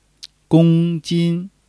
gong1-jin1.mp3